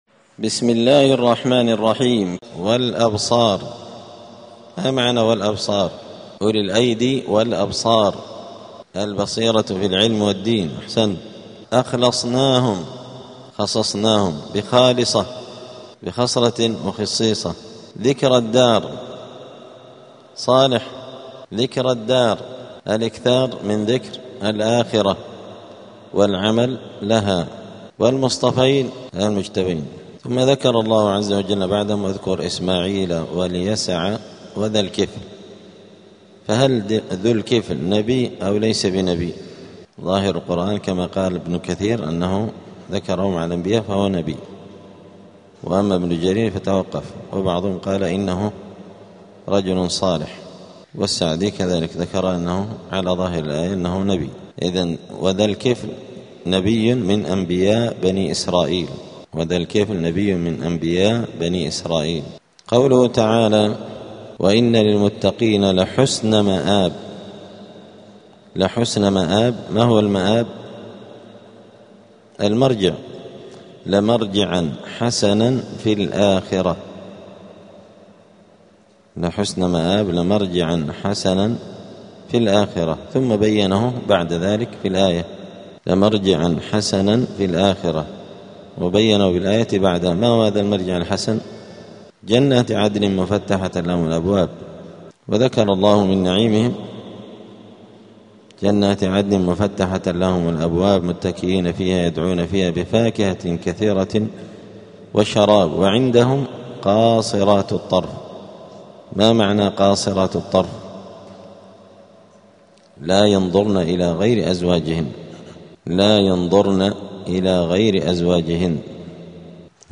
دار الحديث السلفية بمسجد الفرقان قشن المهرة اليمن
الأثنين 5 جمادى الأولى 1447 هــــ | الدروس، دروس القران وعلومة، زبدة الأقوال في غريب كلام المتعال | شارك بتعليقك | 6 المشاهدات